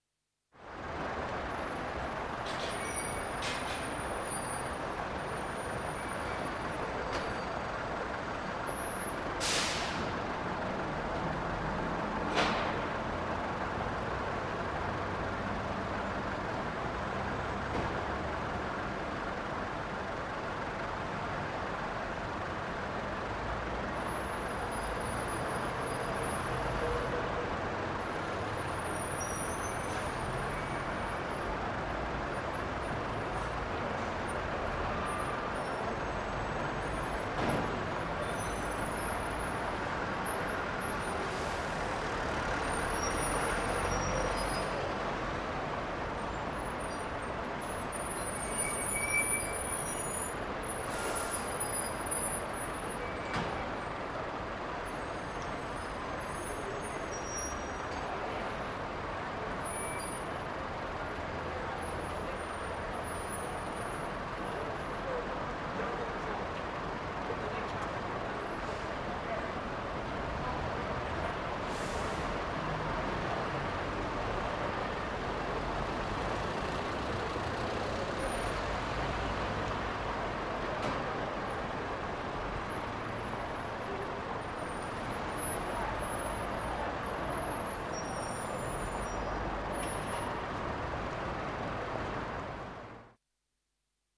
Звуки автовокзала
Звук уезжающего автобуса в Лондоне